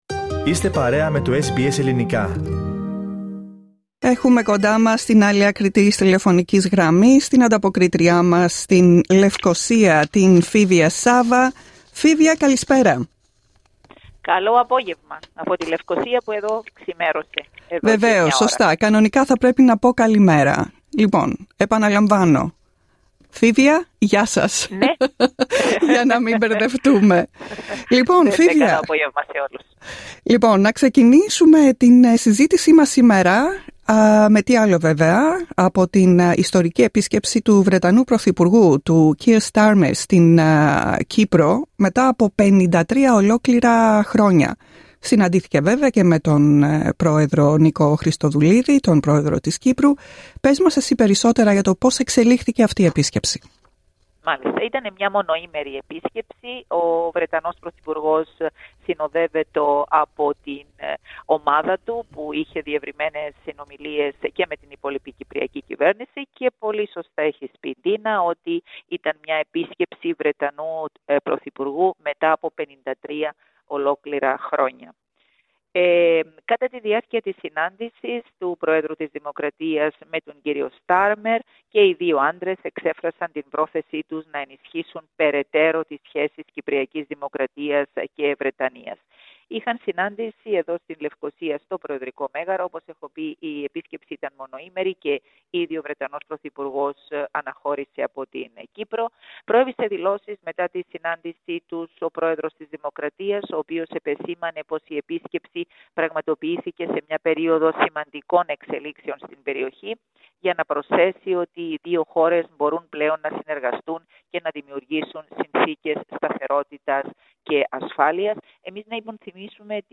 Ακούστε αναλυτικά την εβδομαδιαία ανταπόκριση από την Κύπρο